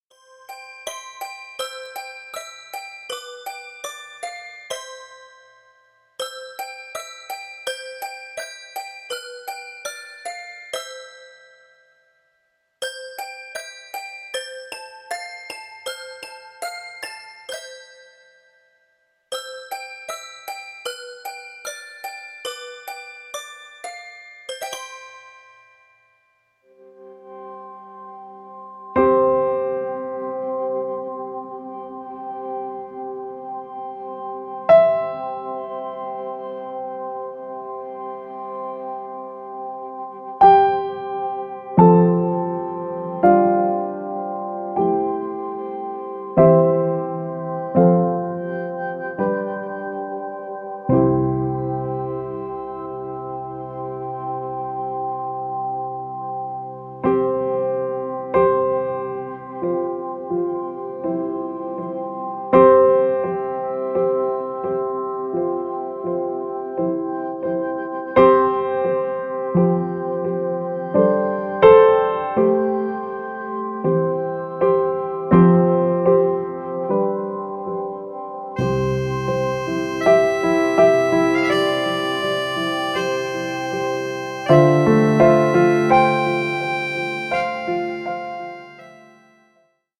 Die schönsten Gute-Nacht-Lieder für Kinder.